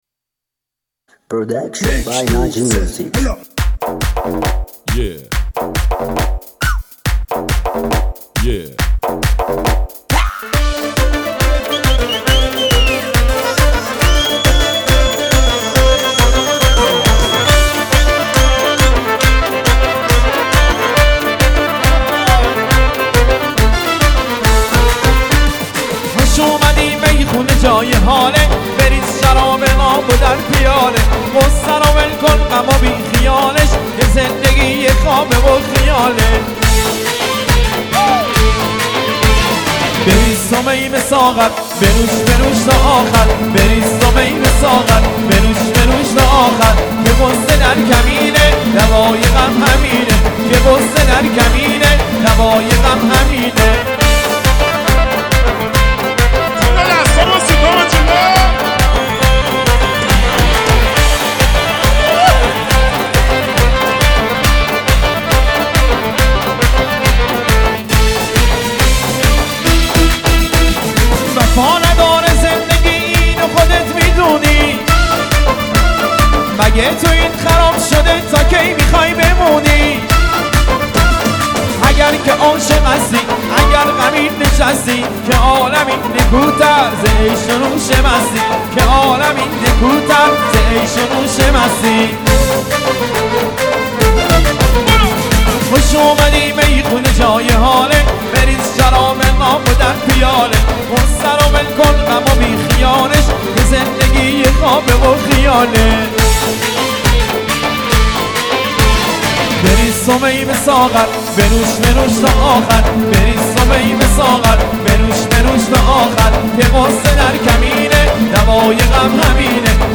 شاد ارکستی